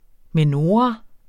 Udtale [ meˈnoːʁɑ ]